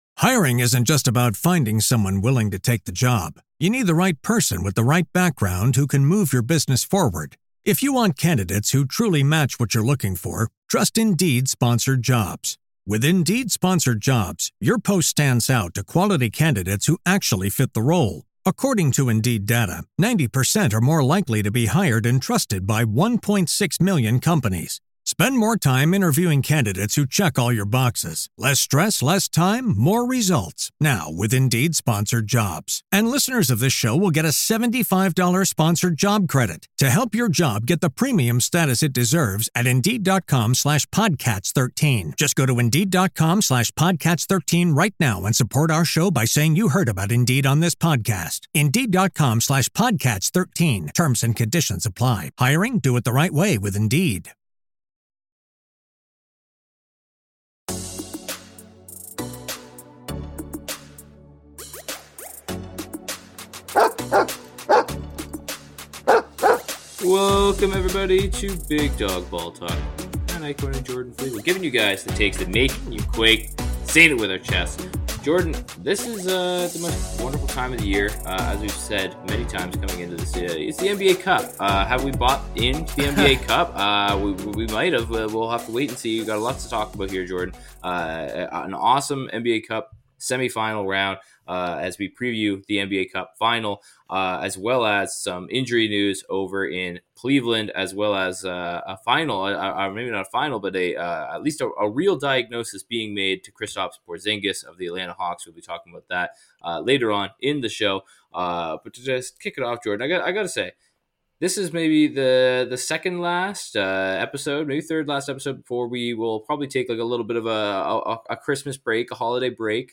Two long-time friends talk NBA Hoops, talk trash and reminisce over obscure moments and players of the sport only they give a damn about.